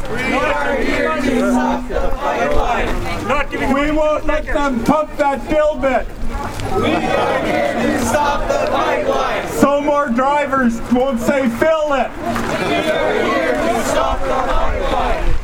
About 70 people walked through the downtown on Saturday afternoon holding signs and chanting.
protest-1.wav